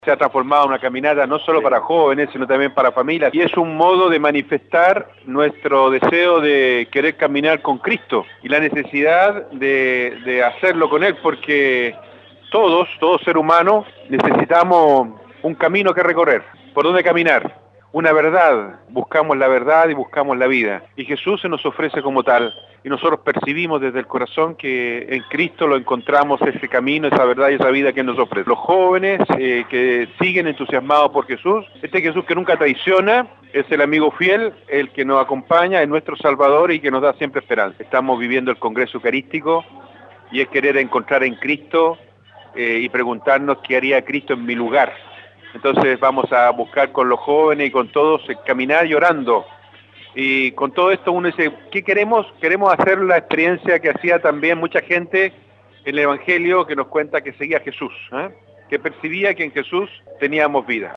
Desde la localidad de Nercón hasta el templo San Francisco los jóvenes y sus familias dieron vida a esta jornada de acompañar a Jesús en su caminar junto al pueblo de Dios, como lo expresó el padre Obispo de la Diócesis san Carlos de Ancud, Juan María Agurto.
cuña-obispo.mp3